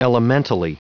Prononciation du mot elementally en anglais (fichier audio)
elementally.wav